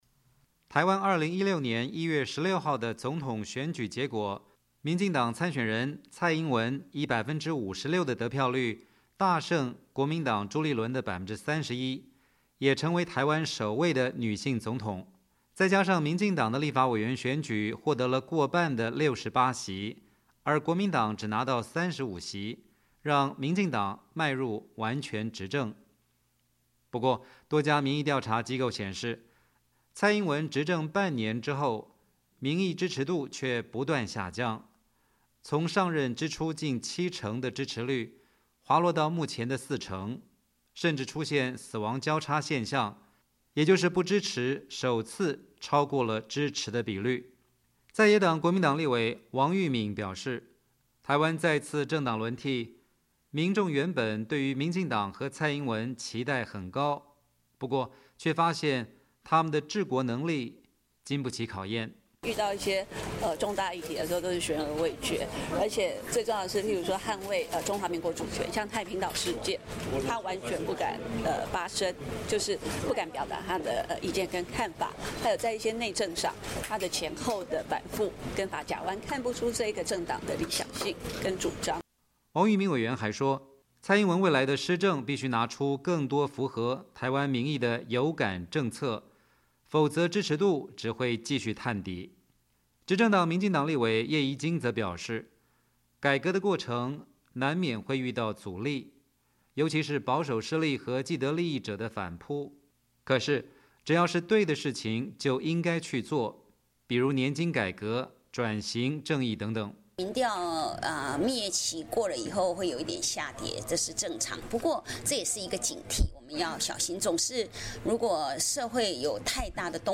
年终报道